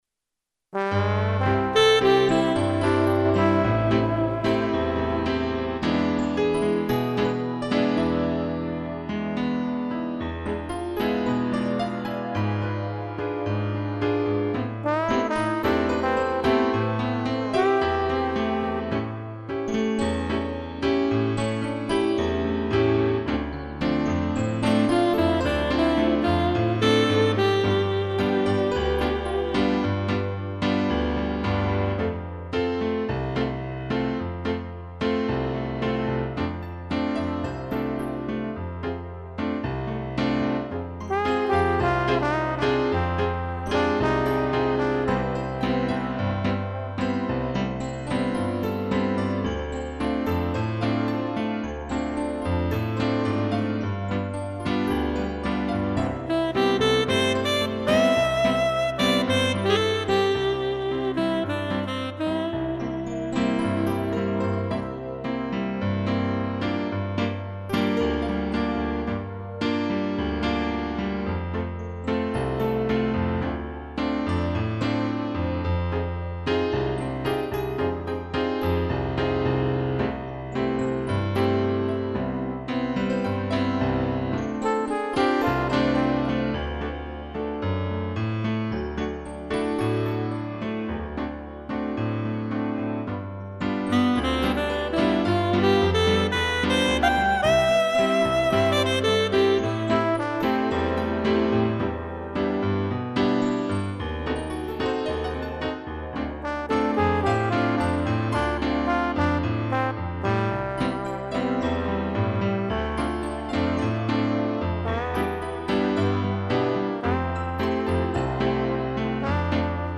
piano, piano acústico, trombone e sax
(instrumental)